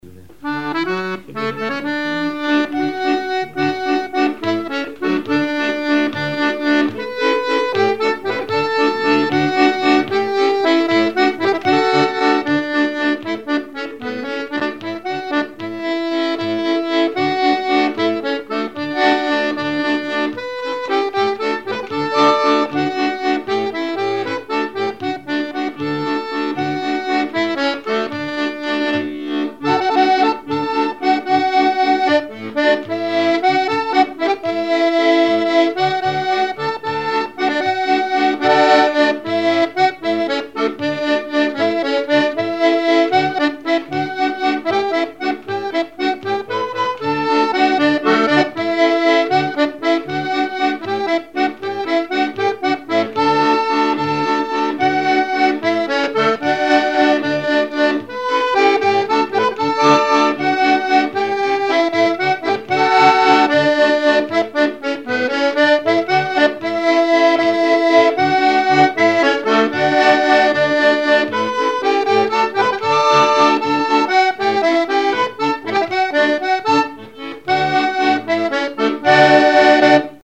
danse : valse
instrumentaux à l'accordéon diatonique
Pièce musicale inédite